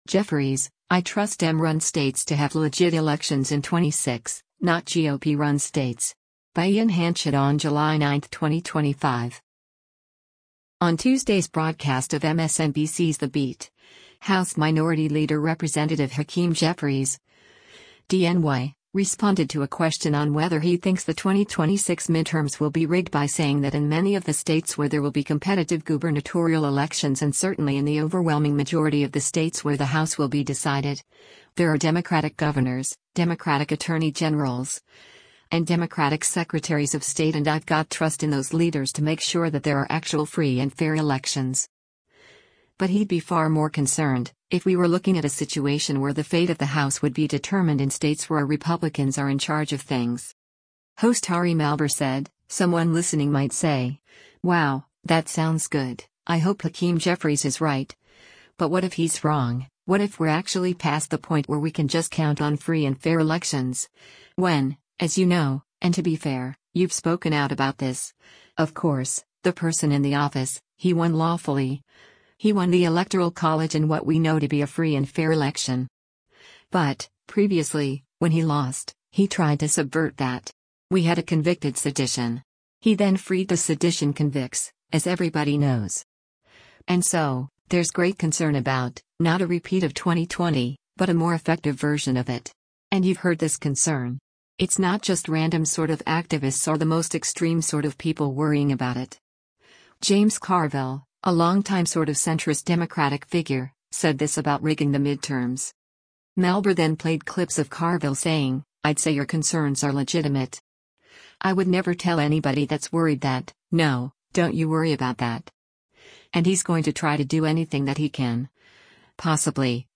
On Tuesday’s broadcast of MSNBC’s “The Beat,” House Minority Leader Rep. Hakeem Jeffries (D-NY) responded to a question on whether he thinks the 2026 midterms will be rigged by saying that “in many of the states where there will be competitive gubernatorial elections and certainly in the overwhelming majority of the states where the House will be decided, there are Democratic governors, Democratic attorney generals, and Democratic secretaries of state” “And I’ve got trust in those leaders to make sure that there are actual free and fair elections.”